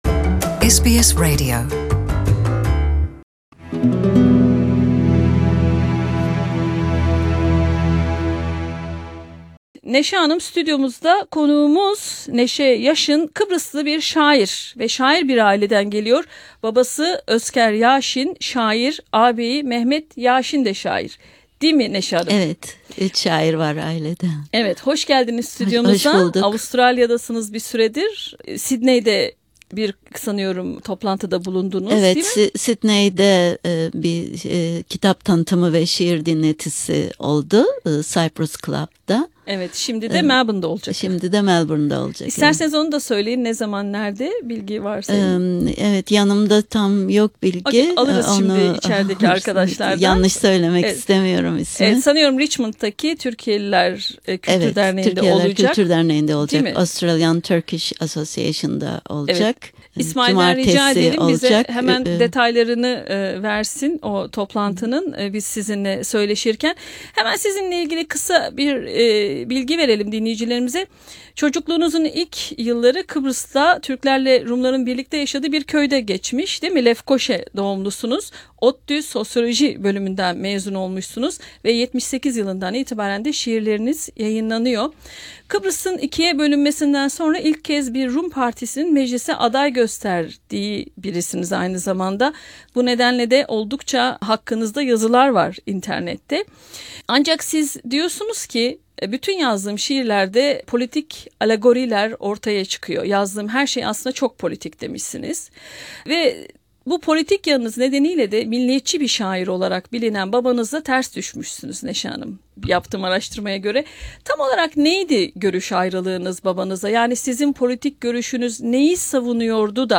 Canlı yayında stüdyo konuğumuz olan Kıbrıs Türk Şair Nese Yasin, 22 Aralık Cumartesi günü Melbourne'ın Richmond semtinde bulunan Türkiyeliler Kültür derneği binasında yapılacak kitap tanıtımında okurlarıyla buluşacak.